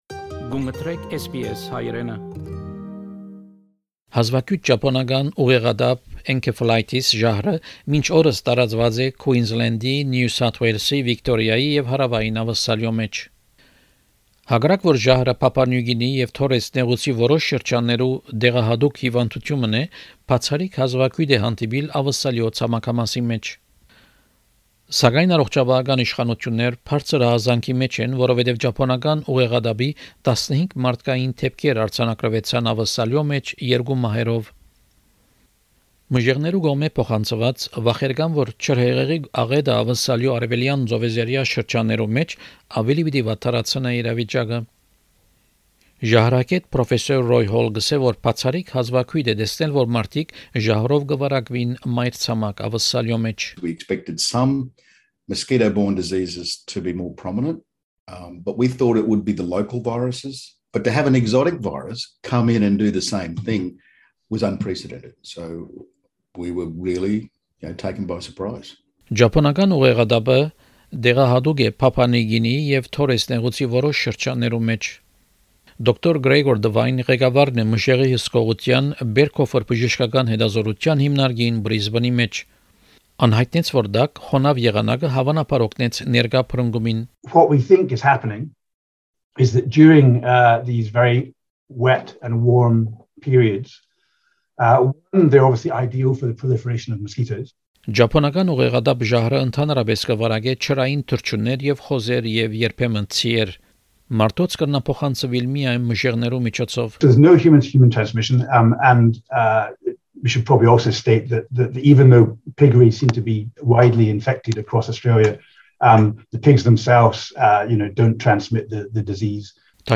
A rare outbreak of Japanese encephalitis [[en-KEFF-uh-lie-tiss]] virus has so far spread to humans in Queensland, New South Wales, Victoria and South Australia. Although the virus is endemic in Papua New Guinea and parts of the Torres Strait, it is extremely rare to see it in mainland Australia.